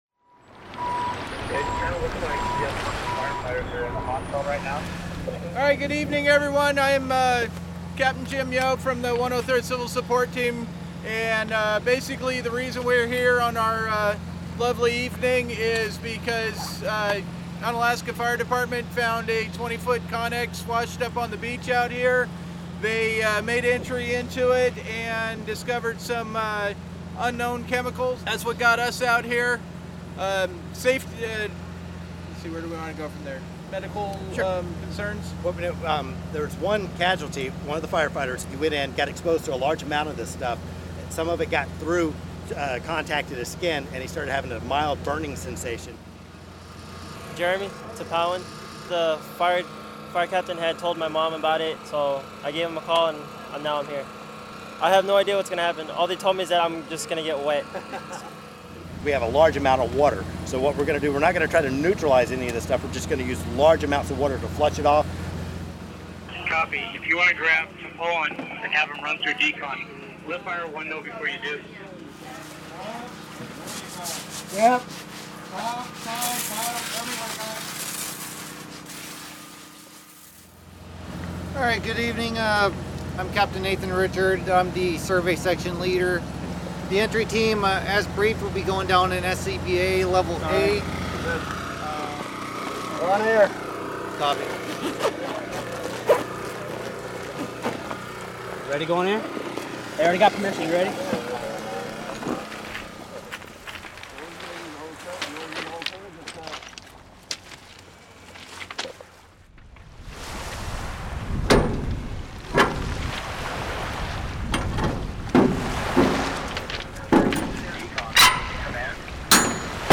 and has this postcard from the scene.